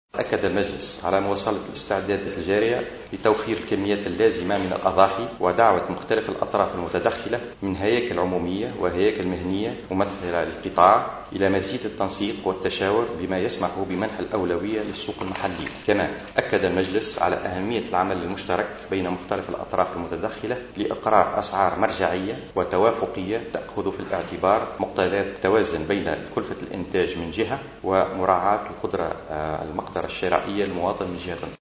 أكد الناطق الرسمي باسم مجلس الوزراء، أحمد زروق، اليوم الاربعاء خلال ندوة صحفية التأمت عقب اجتماع المجلس باشراف رئيس الحكومة، الحبيب الصيد انه تم الاستماع خلال المجلس الى بيان حول الوضع الأمني بالبلاد من قبل وزيري الدفاع والداخلية وبيان اخر من طرف وزير المالية حول تقدم تنفيذ ميزانية الدولة لسنة2015 وبيان ثالث قدمه وزير التجارة حول تزويد السوق والتحكم في الأسعار وتزويد السوق بالأضاحي بمناسبة عيد الاضحى ومنح الأولوية للسوق المحلية.